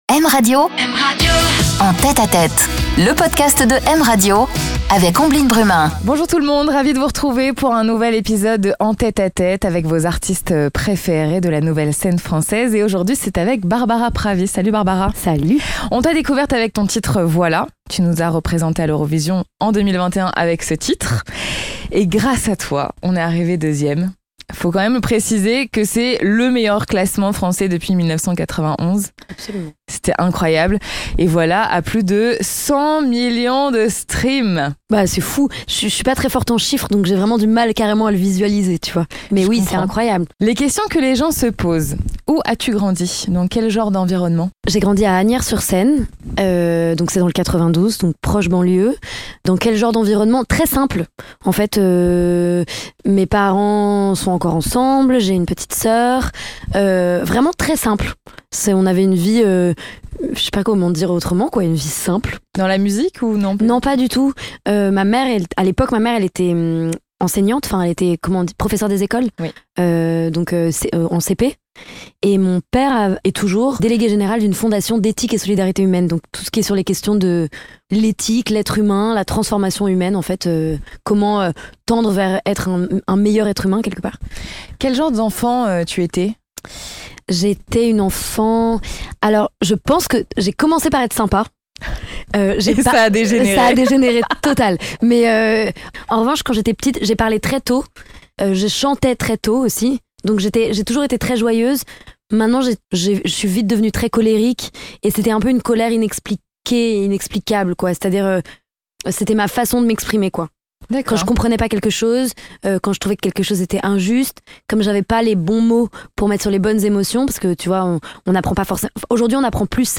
Une interview en confidence, dans l'intimité des artistes